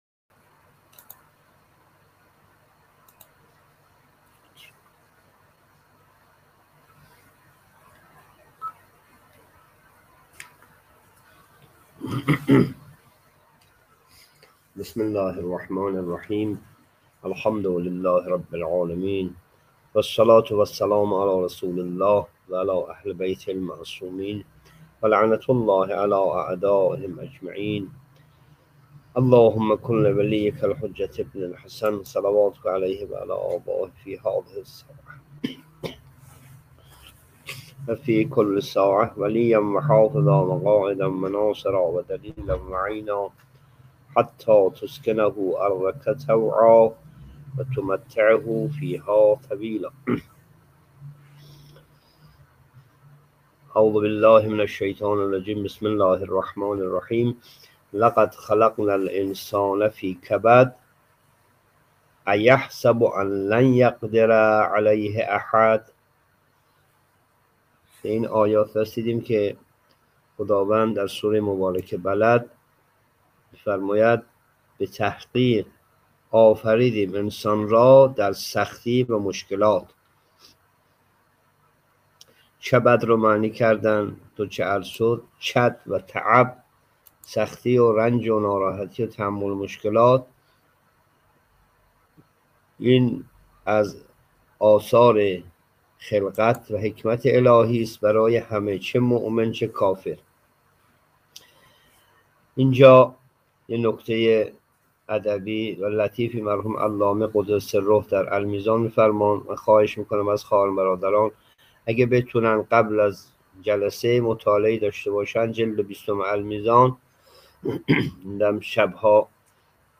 جلسه تفسیر قرآن (25) سوره بلد